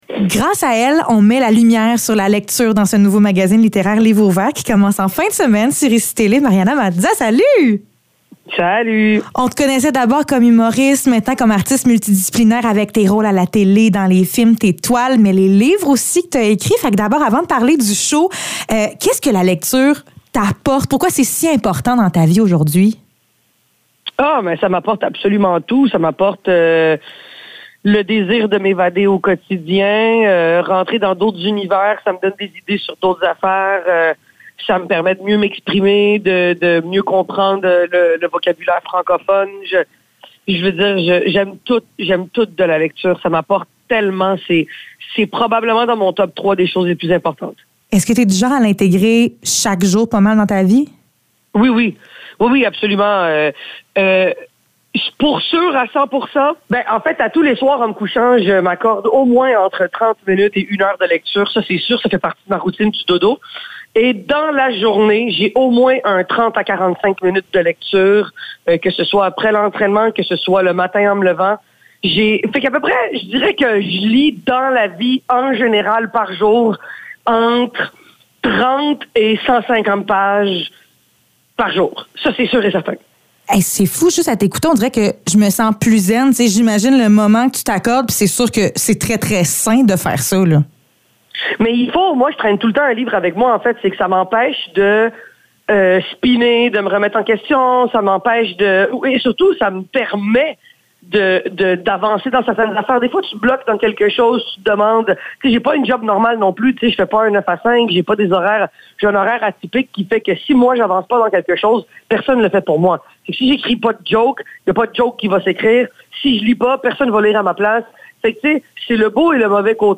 Entrevue avec Mariana Mazza